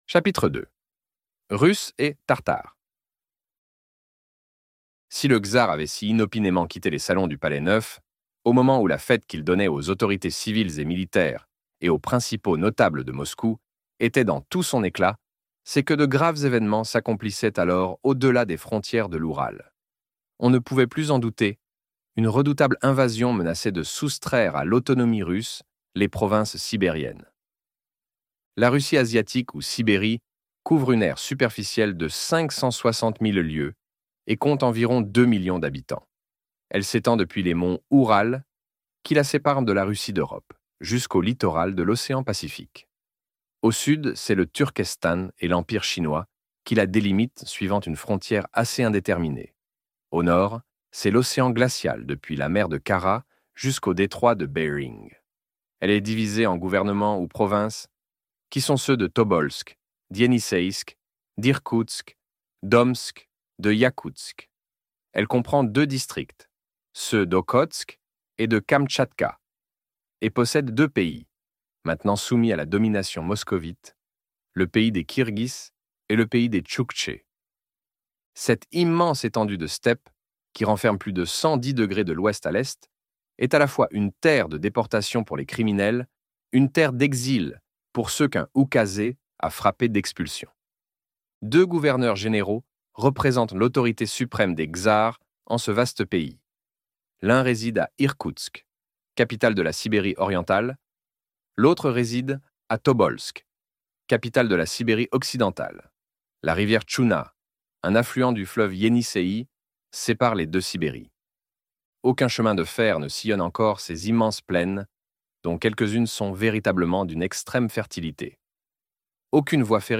Michel Strogoff - Livre Audio